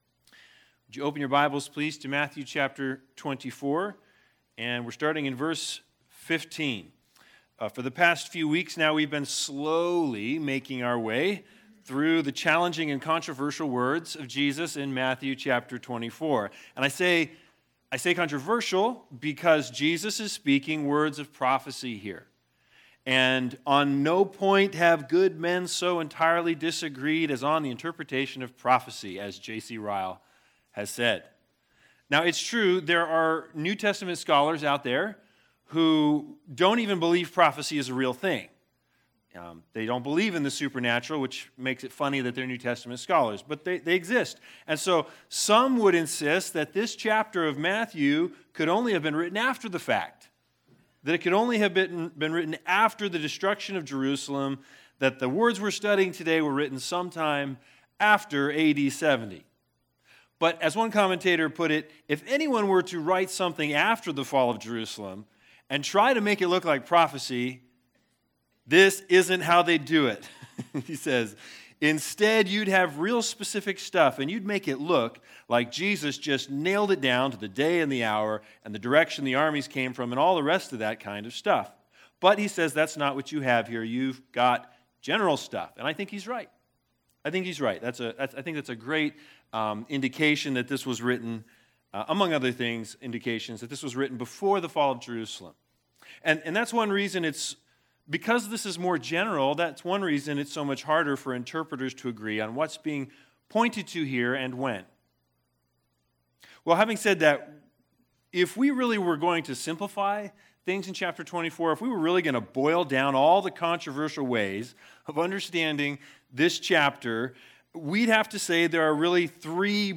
Matthew 24:15-28 Service Type: Sunday Sermons The Big Idea